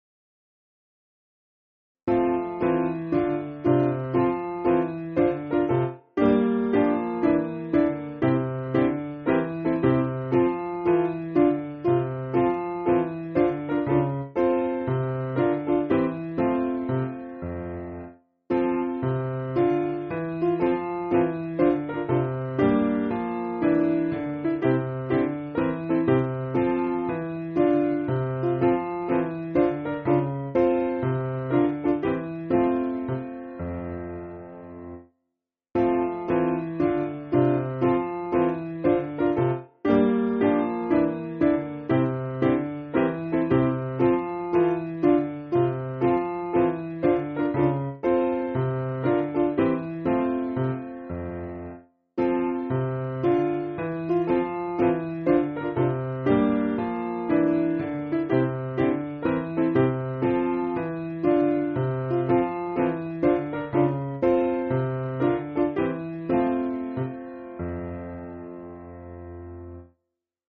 Simple Piano
sing through twice